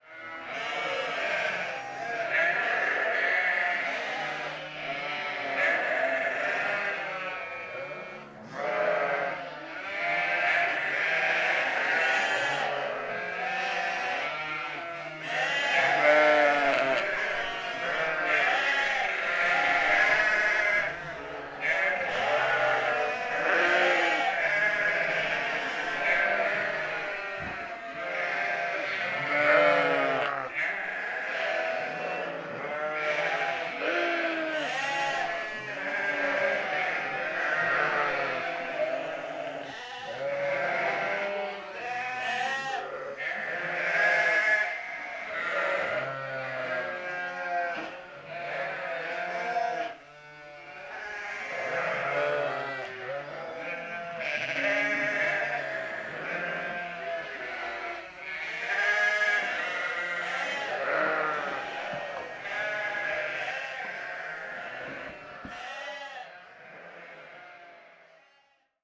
mêêêêêê | l'audio journal
moutons_ansannes.mp3